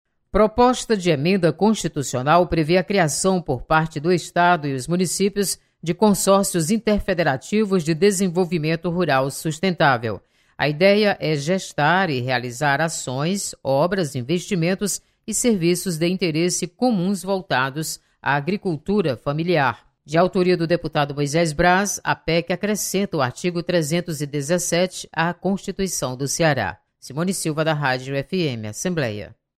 PEC prevê criação de de consórcios de desenvolvimento Rural. Repórter